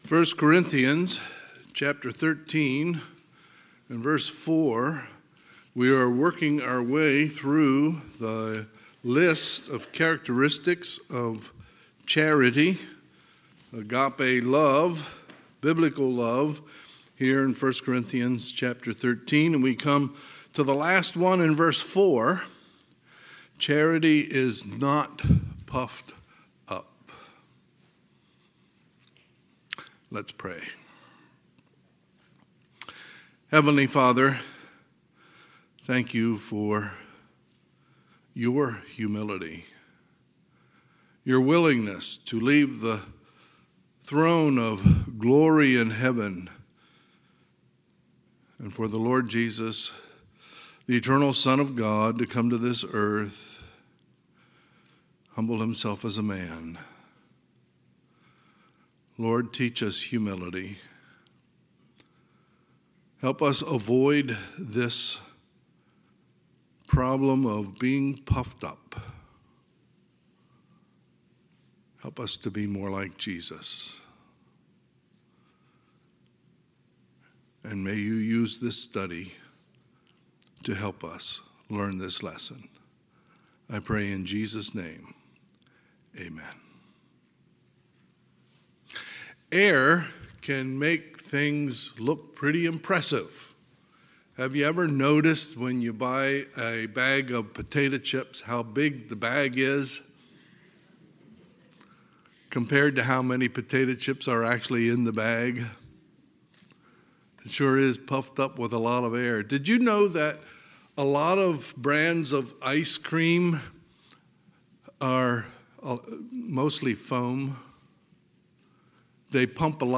Sunday, August 8, 2021 – Sunday AM